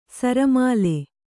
♪ sara māle